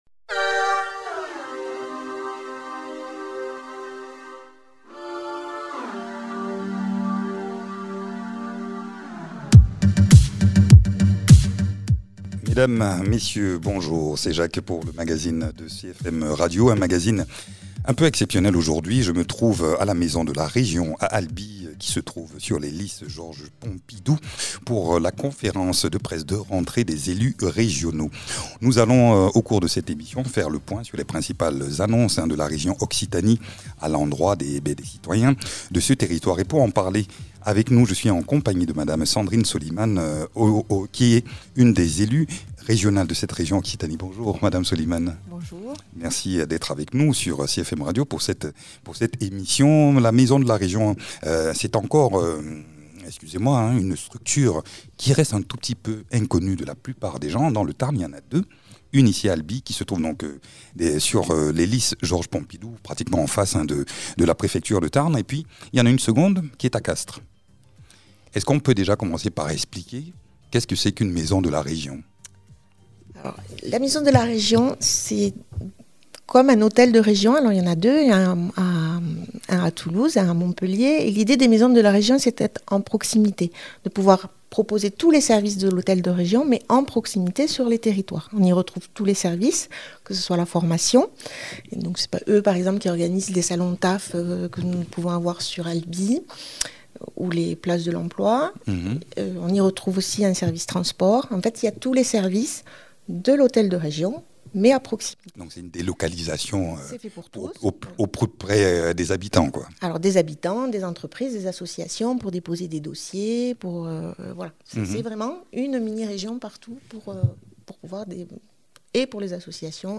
Invité(s) : Sandrine Soliman, élue de la région Occitanie.